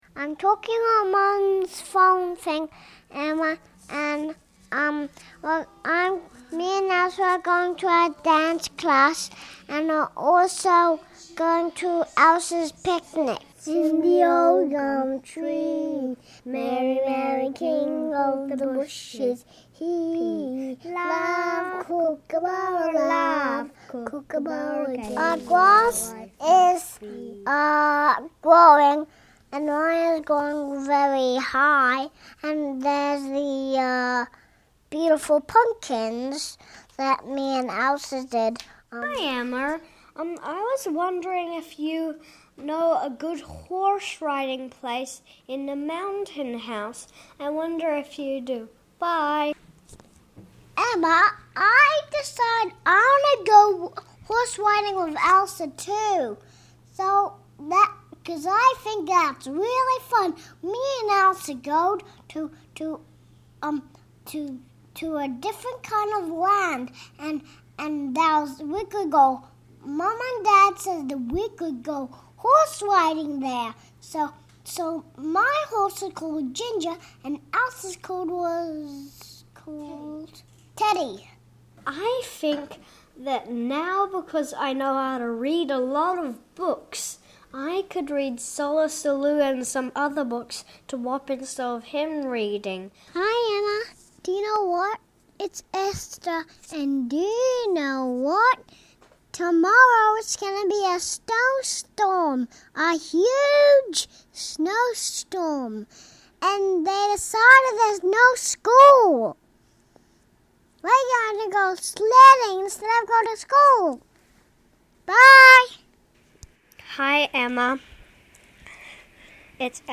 Wander through the gallery and the faint sound of children's voices can be heard...what are they saying?